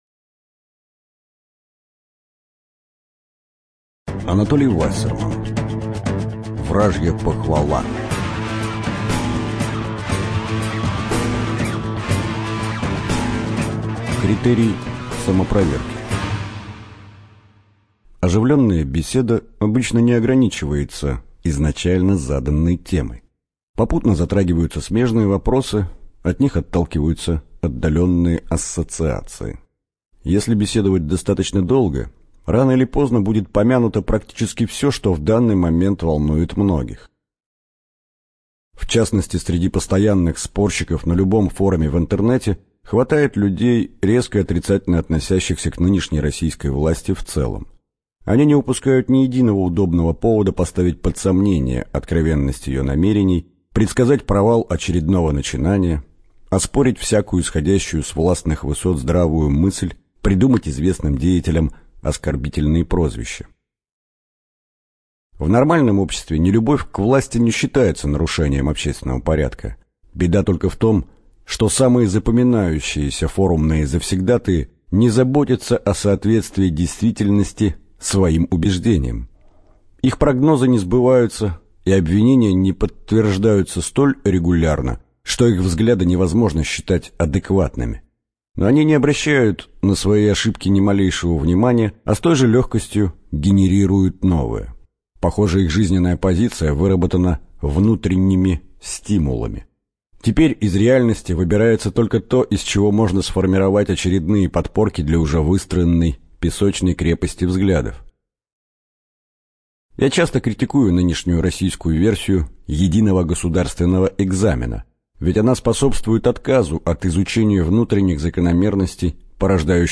Информация о книге Самые интересные факты, люди и казусы всемирной истории, отобранные знатоками (Библиотека ЛОГОС)